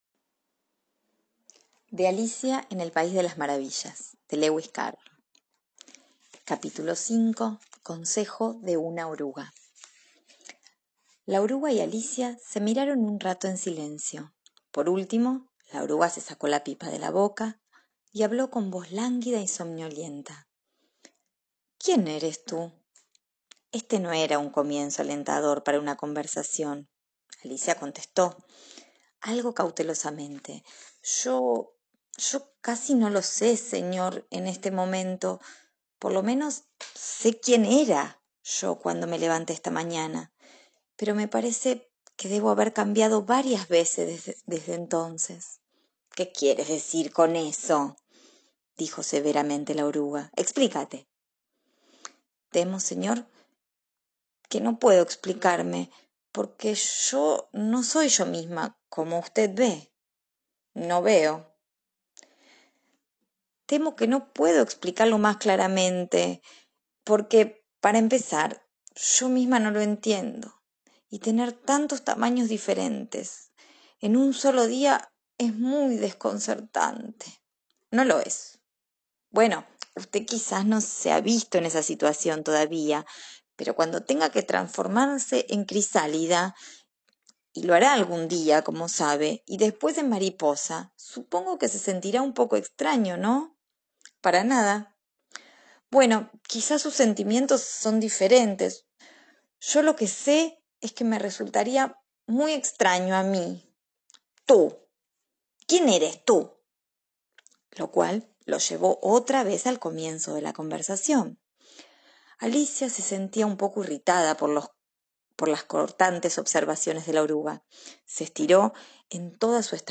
psicoanalista y poeta- nos lee un extracto de la novela «Las aventuras de Alicia en el país de las maravillas» de Lewis Carroll  seudónimo del matemático Charles Lutwidge Dodgson